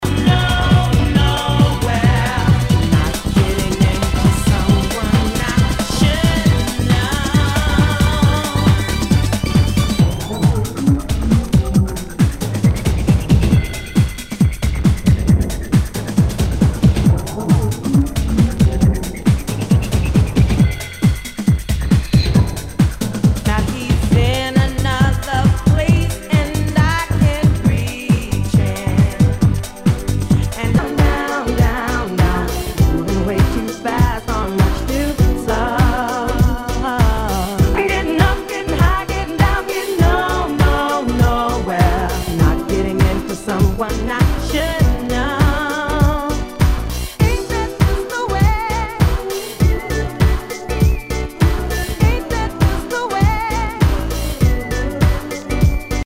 HOUSE/TECHNO/ELECTRO
ヴォーカル・ハウス/ R&B！